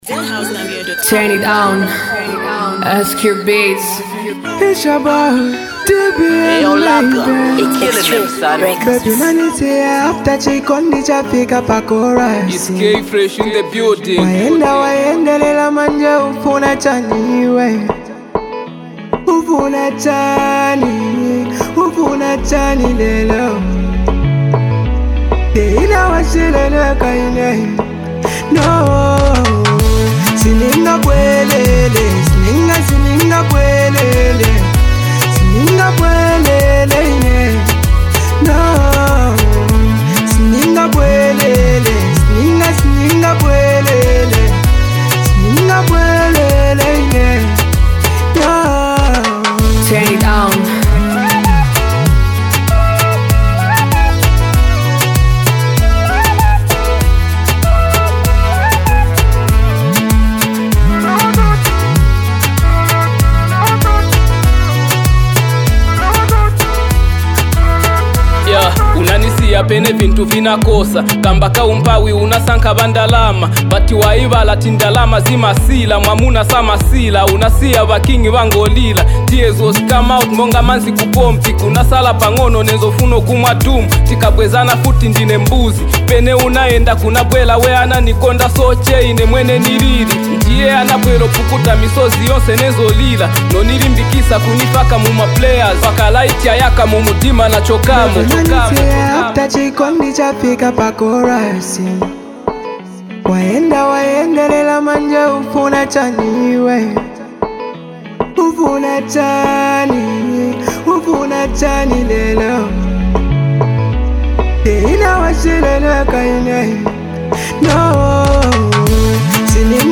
🔥 This track blends powerful lyrics and captivating melodies